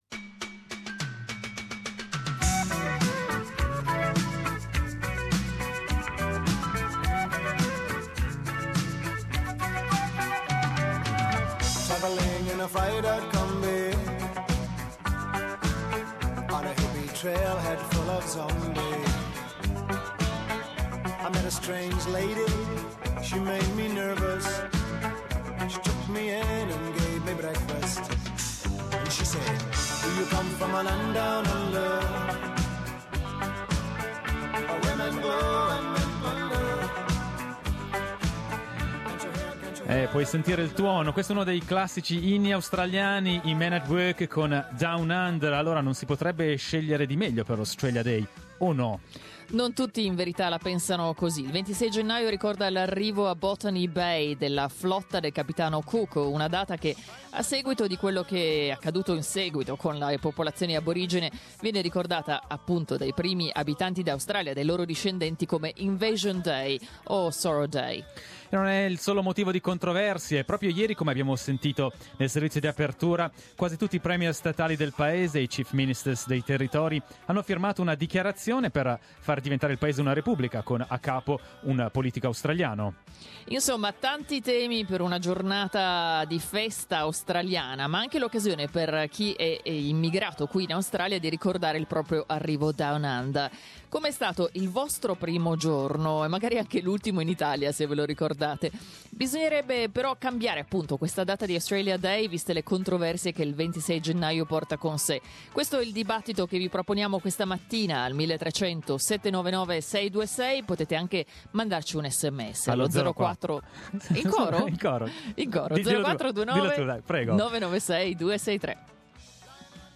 Today, the Australia Day, we asked our listeners to talk about their first day Down Under but also if they think Australia Day should be be celebrated on a different date, as January 26 brings so many disputes with it.